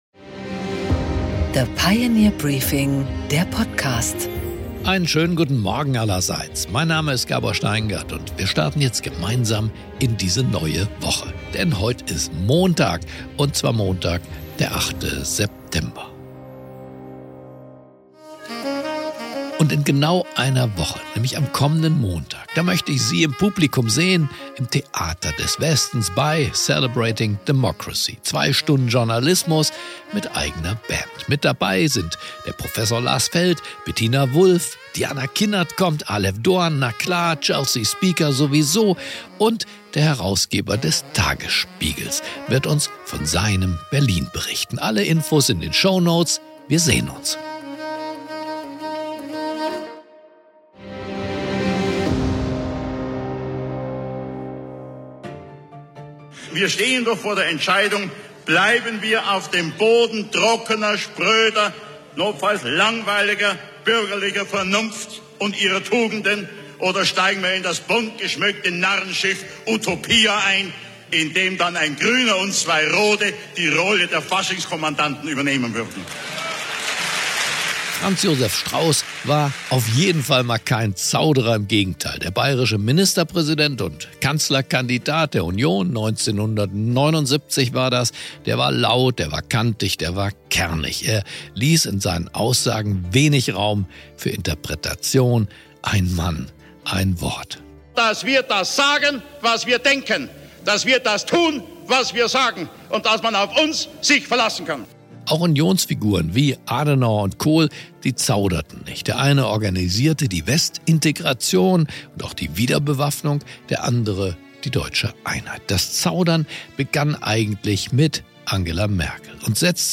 Gabor Steingart präsentiert das Pioneer Briefing
Im Gespräch: Michael Kretschmer , Ministerpräsident von Sachsen, spricht mit Gabor Steingart über die Reformkrise in Berlin, warnende Worte an die Koalition und den neuen sprachlichen Ton in der Politik.